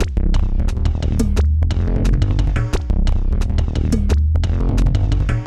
B + D LOOP 1 1.wav